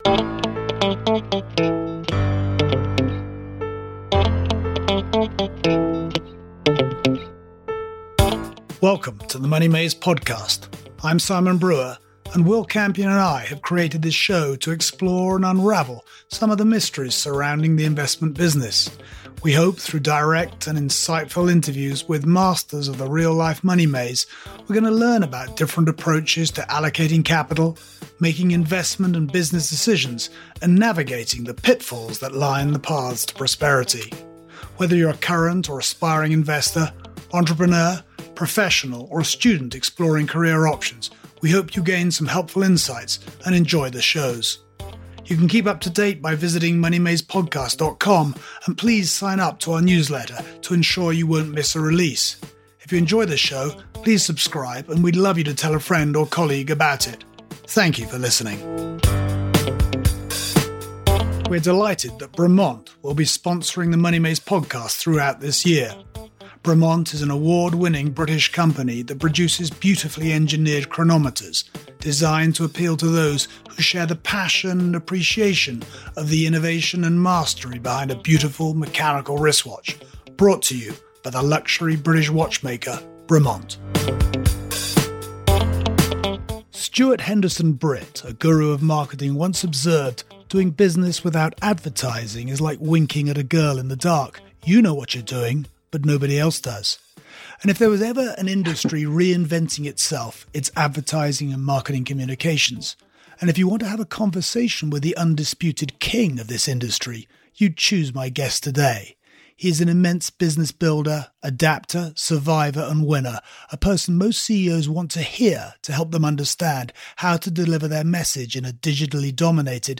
In today’s interview we have the opportunity for a detailed conversation with a man who has been at the forefront of advertising and marketing communications for 5 decades.